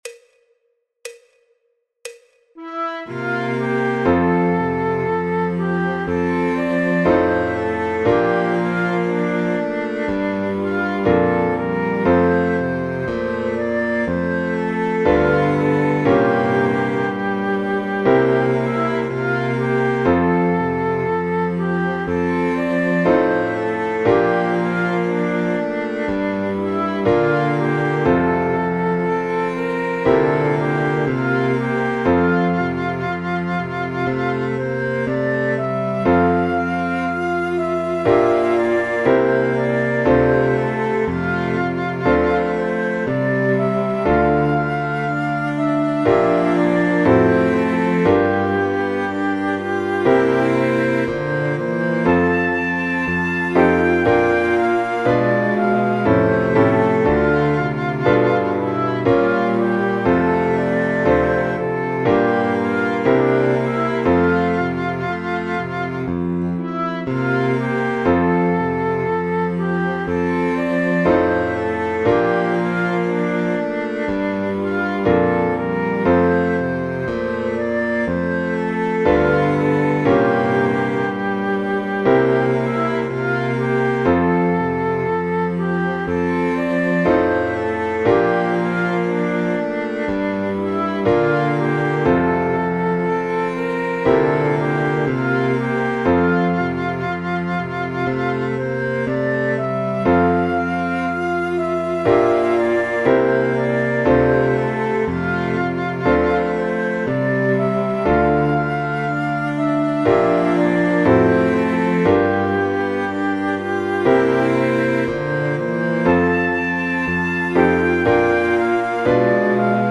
El MIDI tiene la base instrumental de acompañamiento.